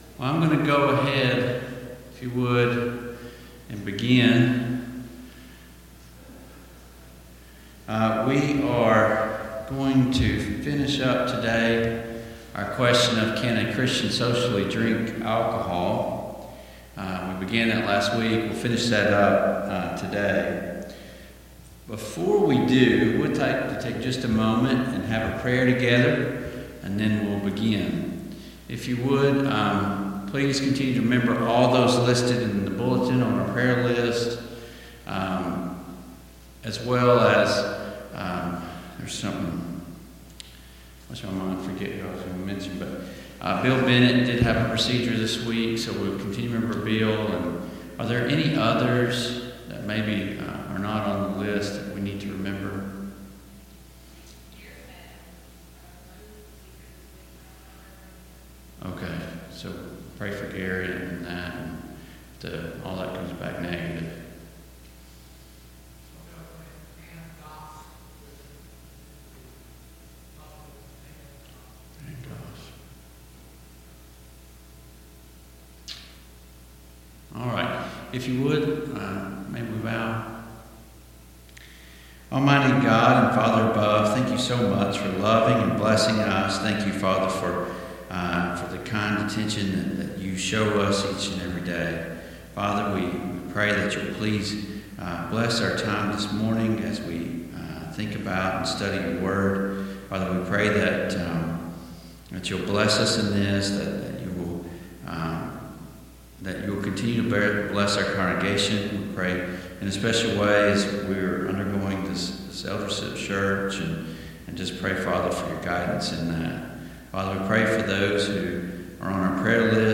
Service Type: Sunday Morning Bible Class Topics: Influence , Intoxication